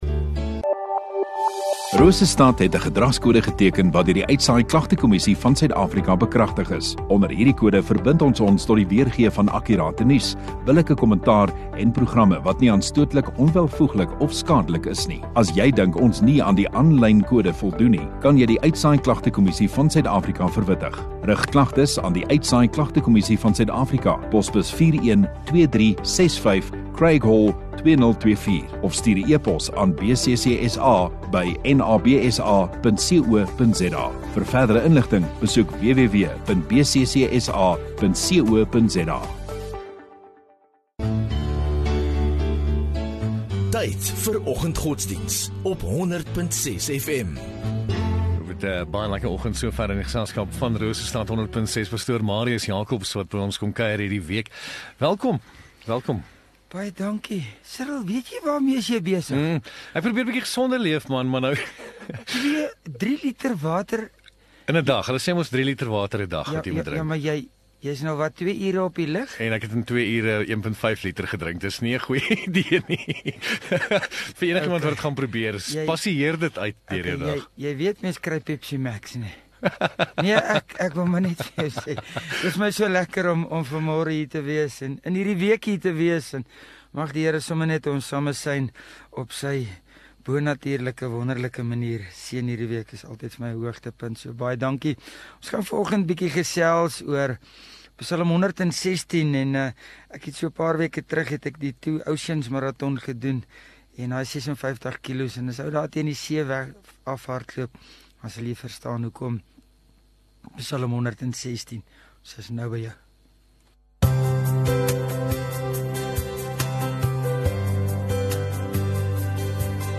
13 May Maandag Oggenddiens